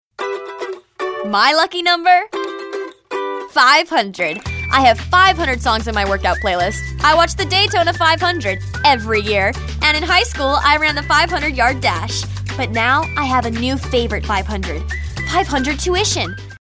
anti-announcer, compelling, confident, conversational, friendly, genuine, real, retail, teenager, upbeat, young adult